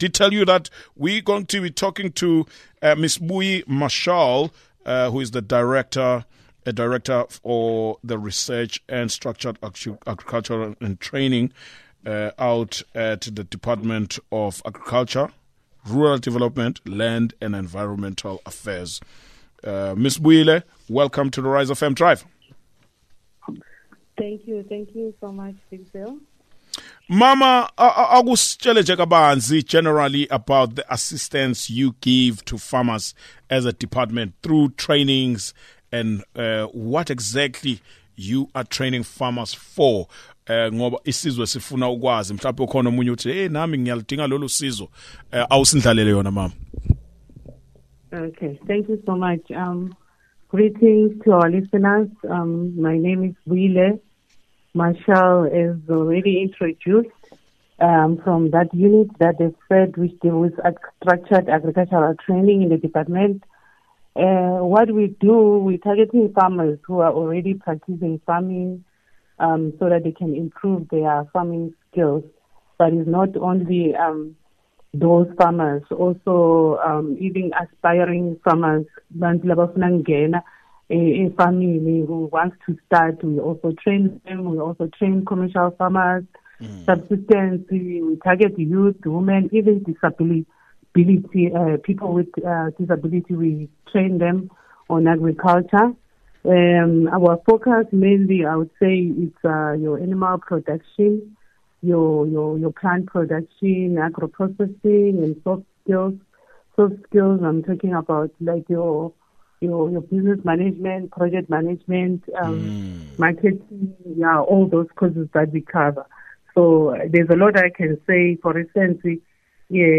RISEfm Drive have a chats with DARDLEA about training and workshop for farmers across the province.